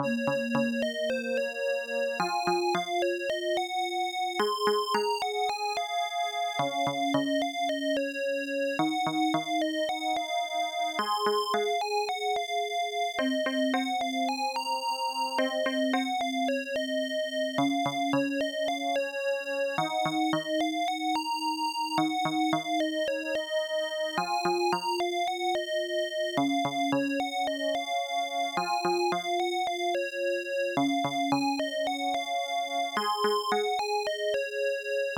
HSC AdLib Composer